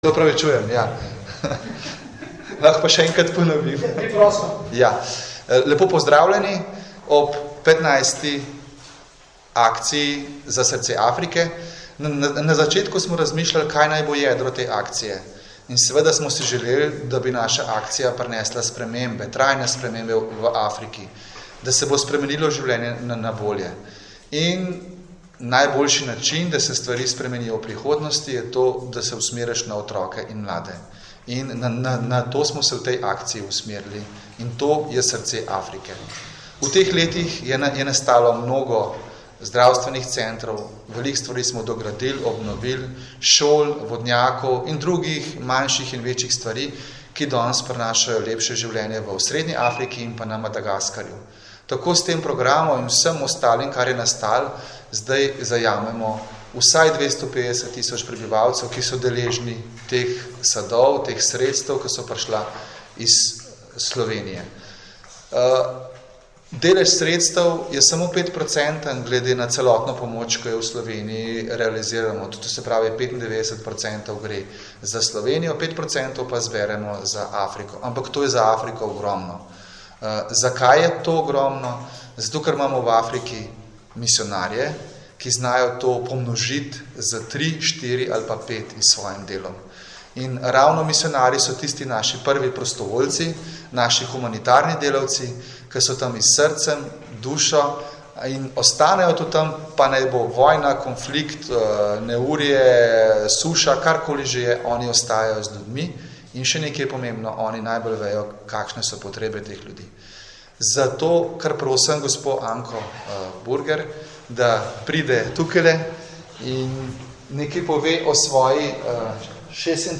Dobrodelna akcija Za srce Afrike 2020 – poročilo z novinarske konference - Slovenska karitas
V prostorih galerije Družina na Krekovem trgu 1 v Ljubljani, je 26. avgusta 2020 potekala novinarska konferenca, na kateri smo predstavili dobrodelni akciji Slovenske karitas z naslovom Za srce Afrike in Z delom do dostojnega življenja s katerima začenjamo vsakoletno v drugi polovici avgusta. Namenjena je humanitarni in razvojni pomoči najrevnejšim otrokom in družinam v Afriki, ki zaradi splošne revščine, posledic podnebnih sprememb ali konfliktov nimajo osnovnih dobrin in pogojev za preživetje in dostojno življenje.